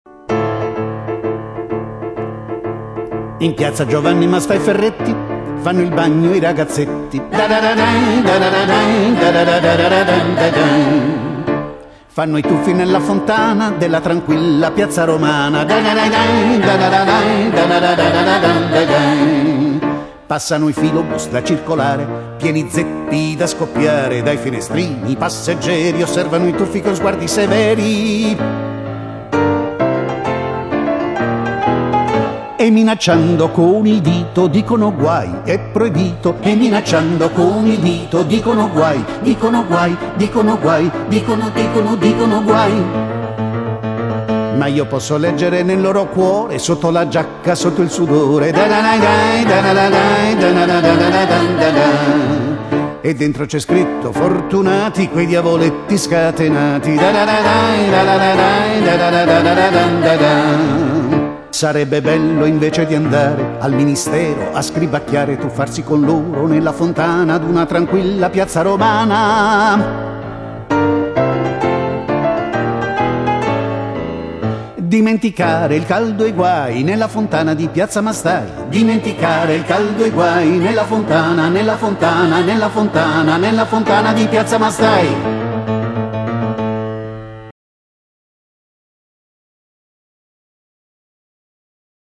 Audio dagli spettacoli e dai seminari in cui sono stati coinvolti gli allievi e gli insegnati dell'Accademia Musicale Giuseppe Verdi